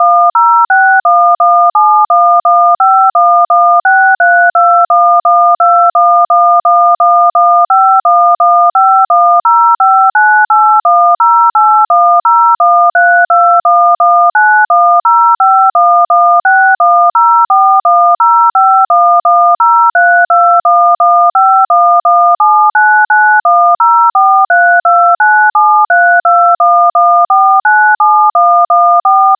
The audio file sounds like a series of DTMF sounds.